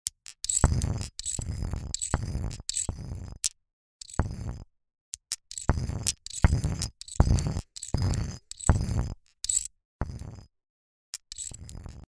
Bei Petroglyph nutze ich den On-Board-Arpeggiator. Den Swell-Regler steuere ich nach MIDI-Learn über einen externen Controller und bringe damit einen fließenden Dynamikverlauf ins Spiel.